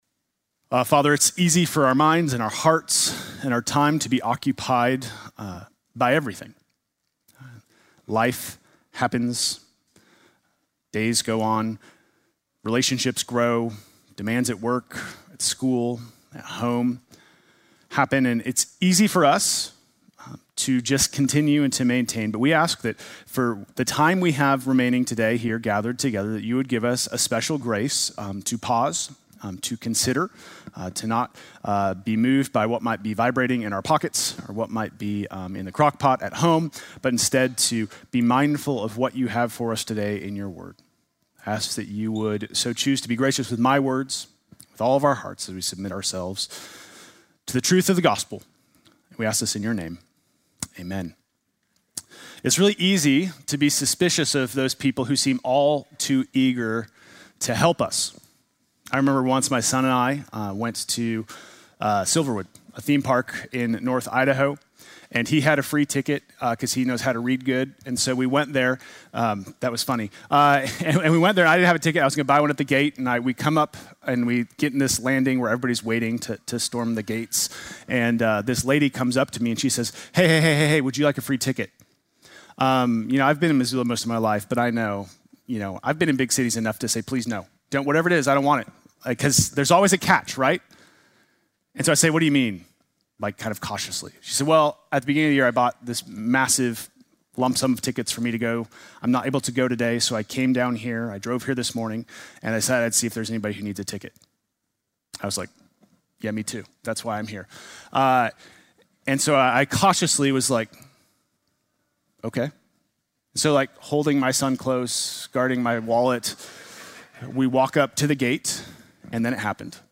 Sunday morning message November 9